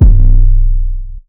808 (Amen).wav